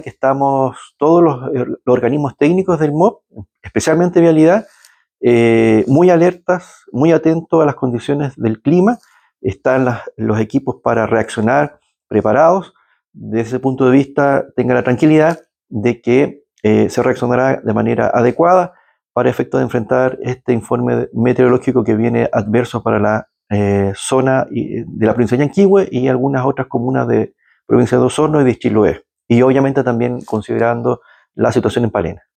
El Seremi de Obras Públicas, Juan Fernando Alvarado, señaló que los organismos técnicos del MOP, están alertas a las condiciones del clima. para efectos de enfrentar el informe meteorológico que viene adverso para la zona.